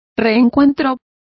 Complete with pronunciation of the translation of reunion.